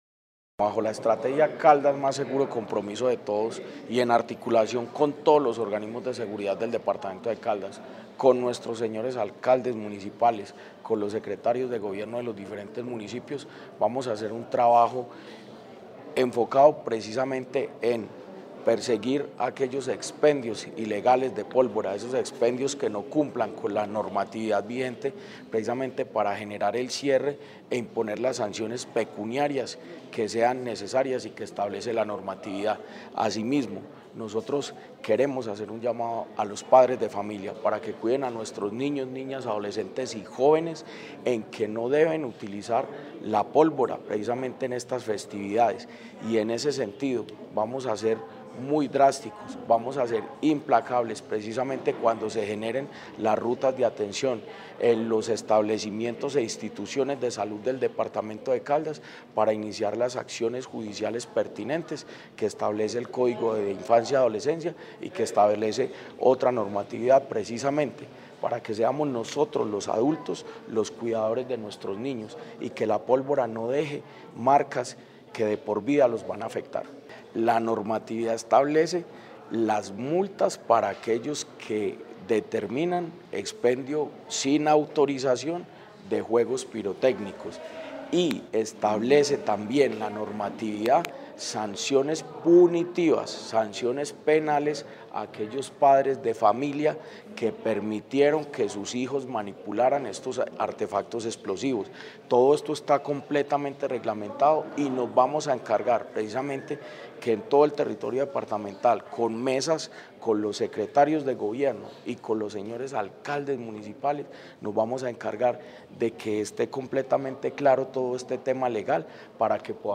Jorge Andrés Gómez Escudero, secretario de Gobierno de Caldas.
AUDIO-JORGE-ANDRES-GOMEZ-ESCUDERO-SECRETARIO-DE-GOBIERNO-DE-CALDAS-TEMA-CAMPANA-POLVORA.mp3